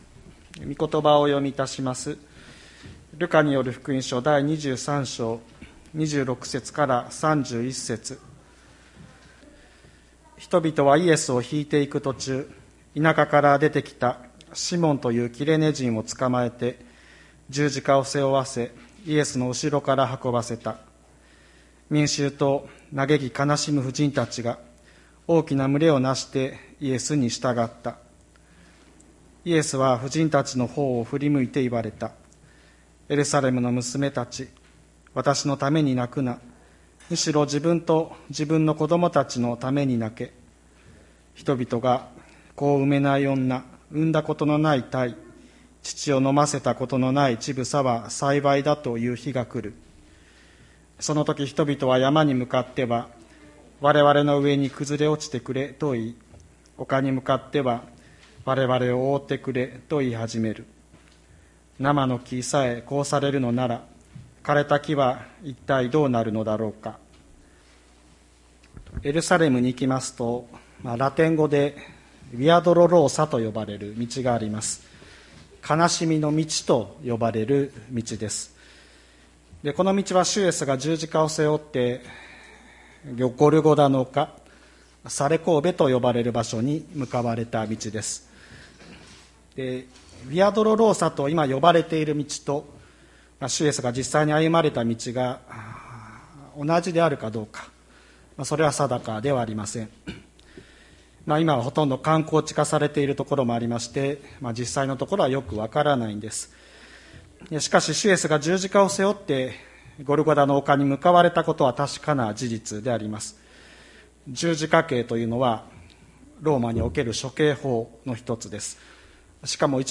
2021年03月21日朝の礼拝「自分のために泣きなさい」吹田市千里山のキリスト教会
千里山教会 2021年03月21日の礼拝メッセージ。